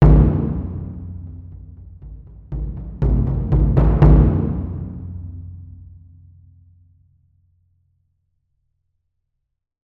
その響きを再現するために、TAIKO THUNDERはスタジオではなくホールでサンプリング収録を行いました。
• Hall：ホールの響きを含んだ、マイクポジションのサウンド（ステレオ）です。